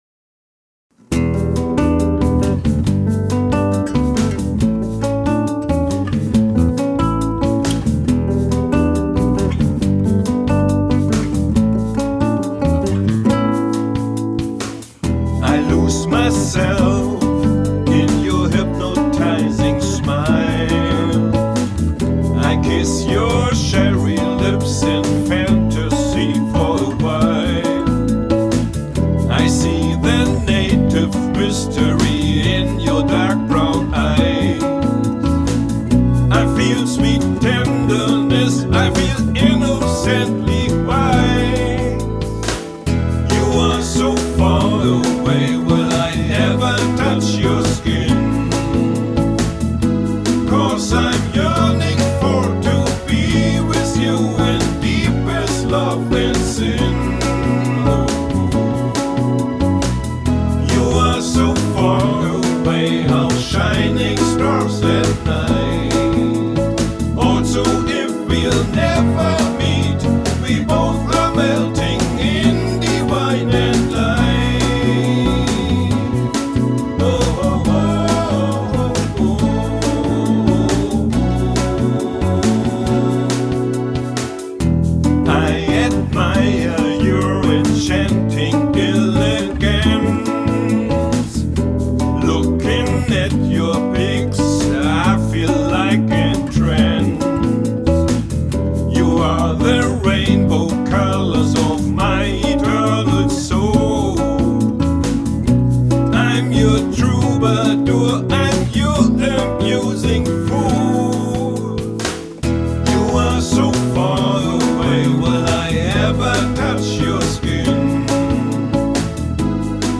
Die Audio-Tracks sind mit allen Instrumenten (und Vocals) von mir allein eingespielt worden (home-recording).
Seit Frühjahr 2012 benutze eine etwas bessere Aufnahmetechnik (mit einem externen Audio-Interface und einem besseren Mikrofon).